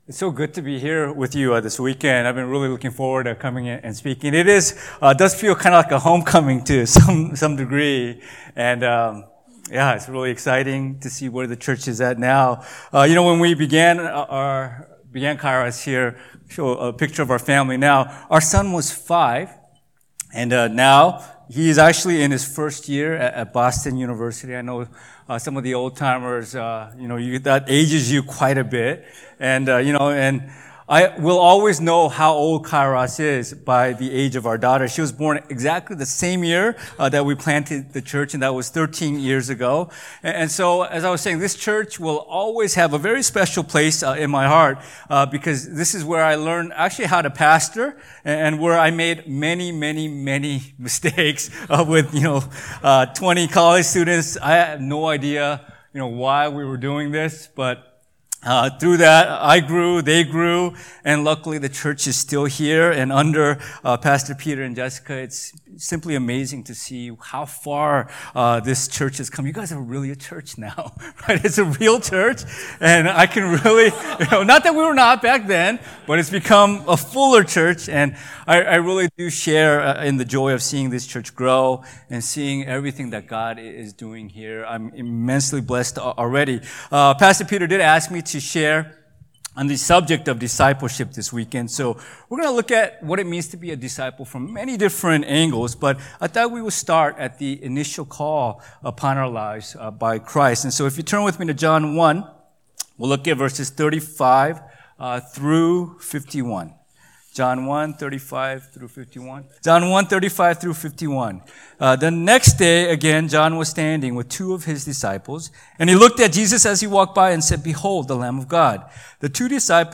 2019 Kairos Retreat - Follow Me